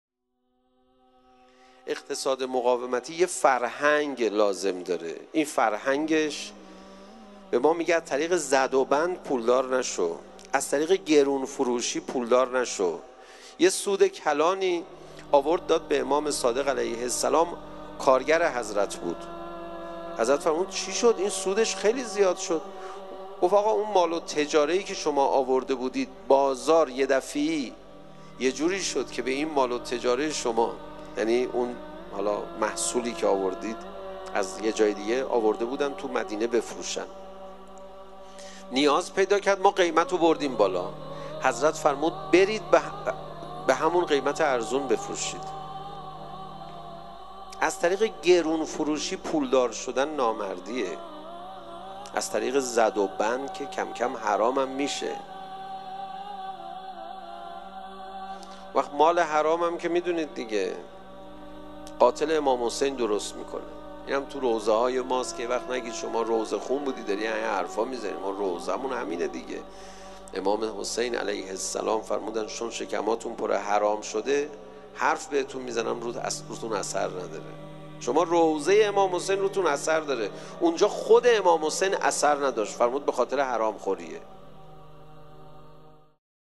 فرازی از سخنان حجت الاسلام پناهیان در حرم مطهر رضوی